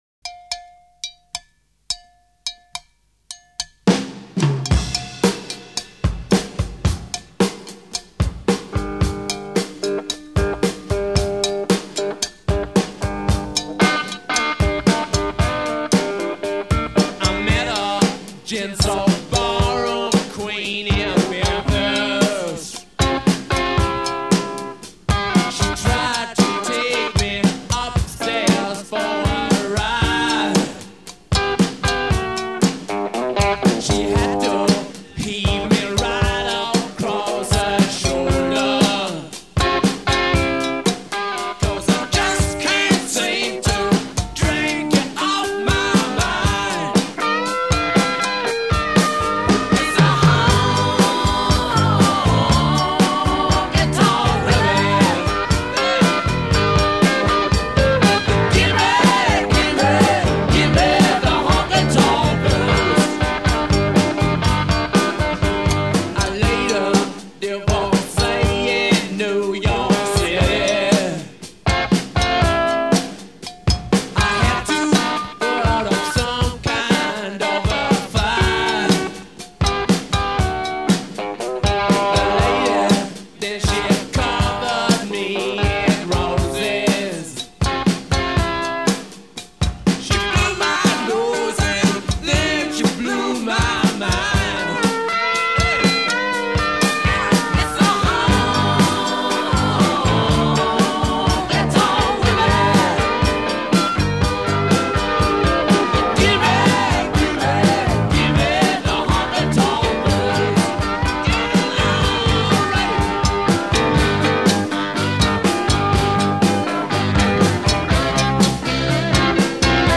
Refrain 4 + 4 Two-part harmonies on vocal; add bass. b
Verse 4 + 4 + 4 + 4 As in verse above; drop out bass. c